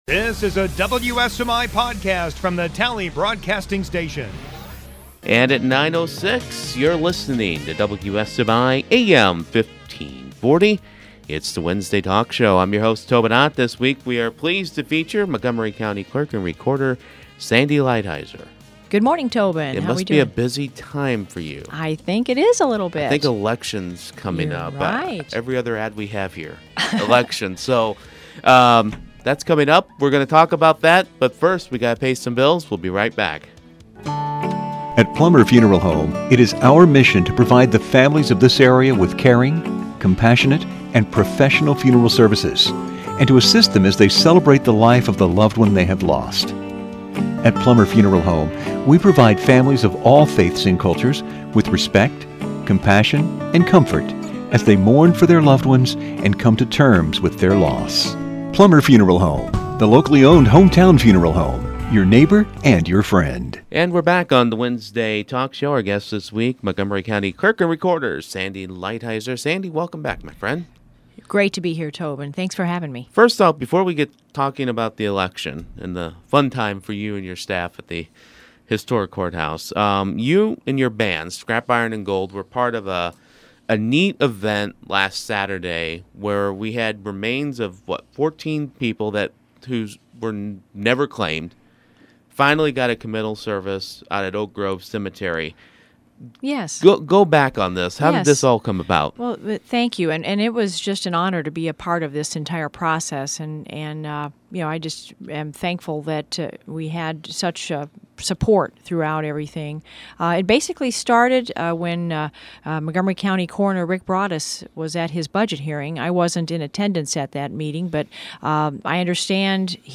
Podcasts - Wednesday Talk